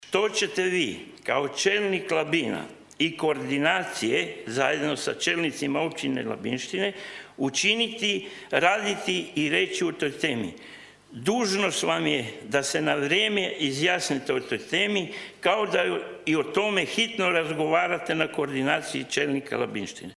Vijećnik Možemo! Mladen Bastijanić na ovotjednoj je sjednici Gradskog vijeća Labina ponovno govorio o nekim najavama da bi se u Plomin Luci, na lokaciji današnje Termoelektrane Plomin, mogla izgraditi nuklearna elektrana.